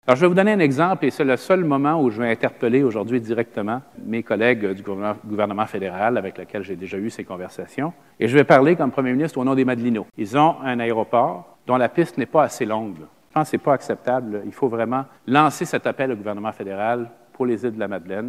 Le premier ministre, Philippe Couillard, annonçait six gestes pour améliorer les conditions du transport aérien en région, en clôture du Sommet tenu à Lévis, vendredi.
Dans son mot de clôture, Philippe Couillard annonçait également la création d’une passerelle de communication auprès du gouvernement fédéral, où il revendique déjà l’allongement de la piste de Havre-aux-Maisons :